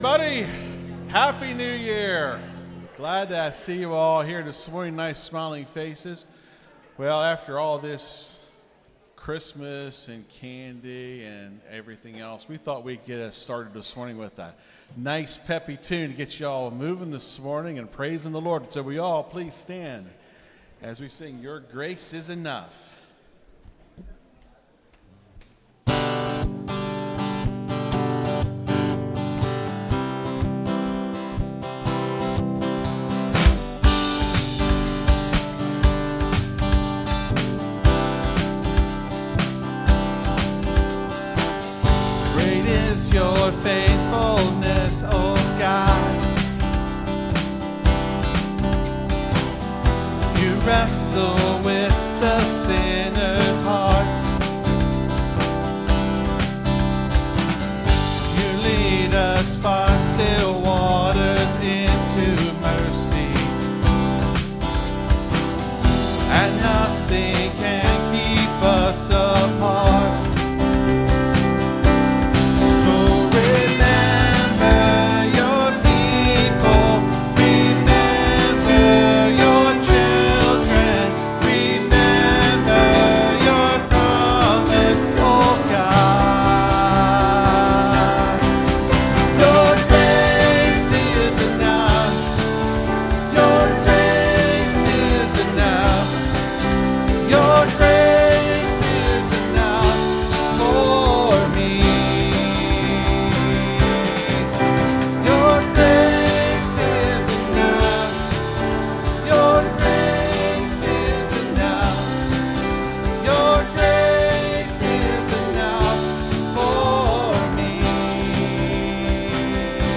(Sermon starts at 23:35 in the recording).